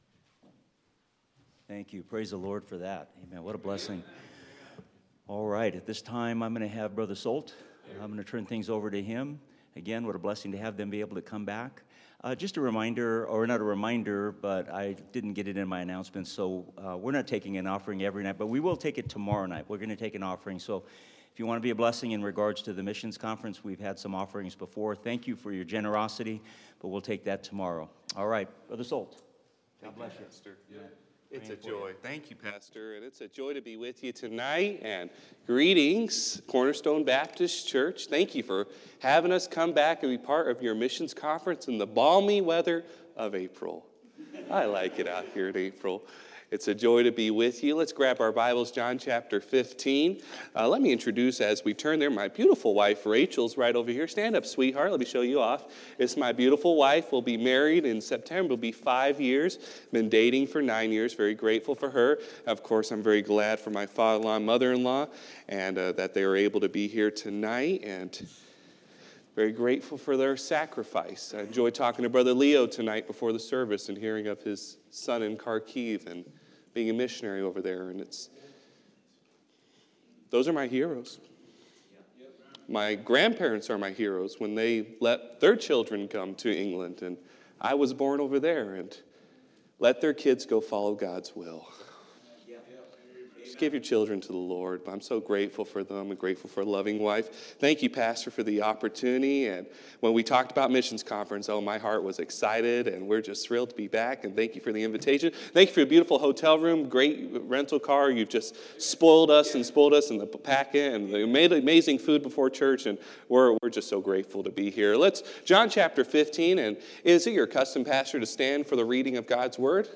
Series: 2024 Missions Conference Passage: John 15